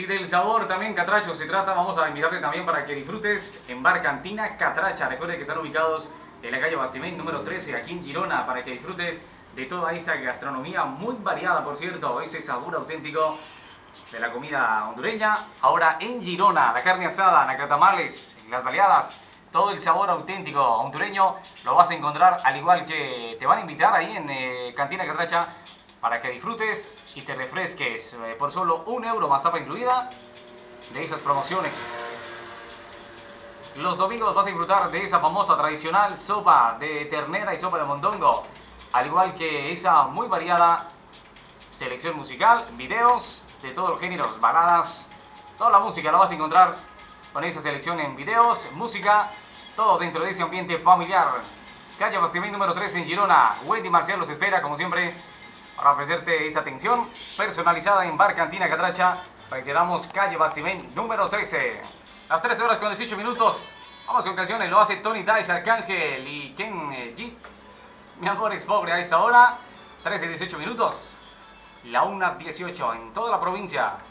Publicitat i hora